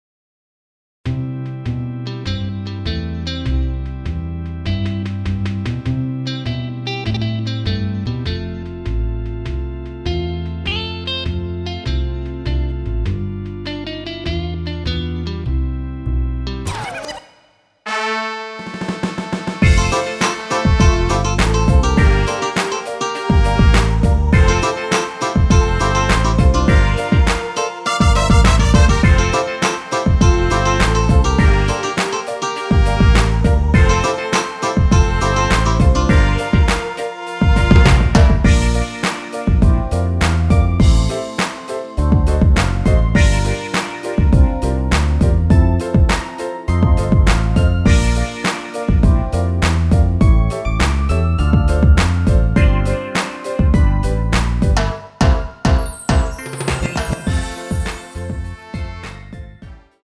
원키에서(-1)내린 랩부분 삭제한 MR입니다.(미리듣기및 본문 가사참조)
앞부분30초, 뒷부분30초씩 편집해서 올려 드리고 있습니다.
중간에 음이 끈어지고 다시 나오는 이유는